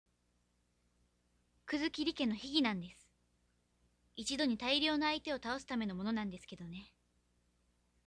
１７歳/女　一人称「私（わたくし）」
ＳＡＭＰＬＥ　ＶＯＩＣＥ
大人びた雰囲気を声でかもし出してください。